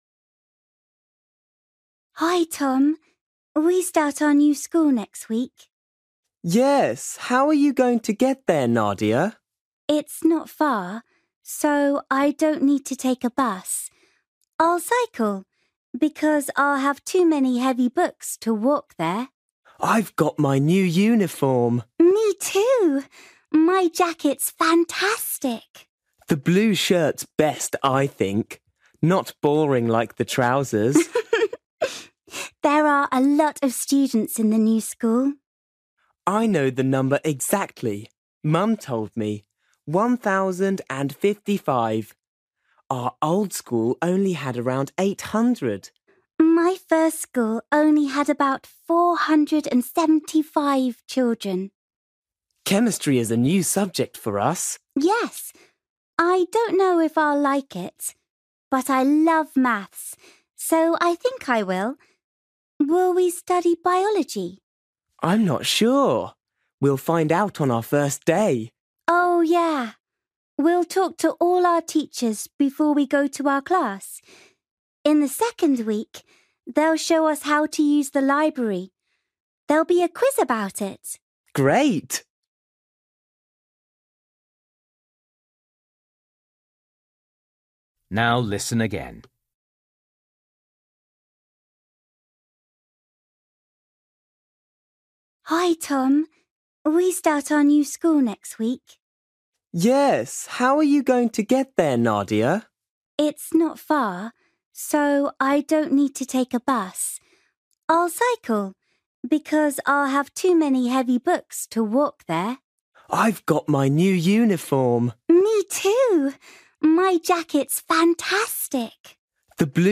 You will hear Nadia and Tom talking about their new school.